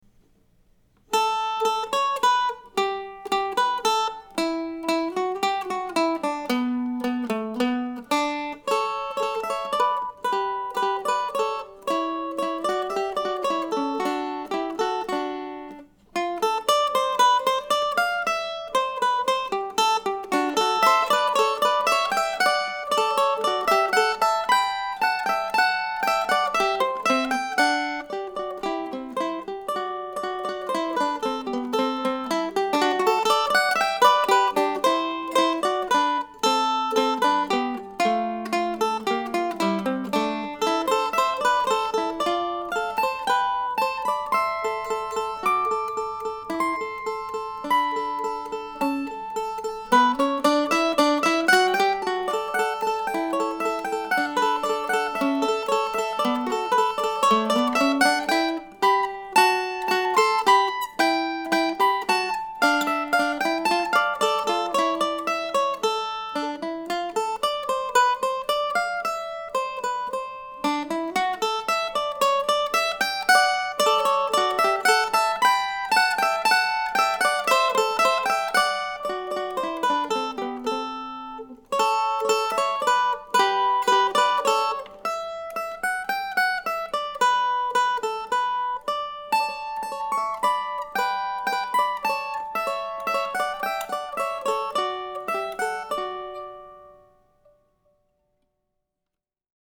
I wrote this little piece for two this week and, when faced with the title dilemma, I thought to call it an "easy duo."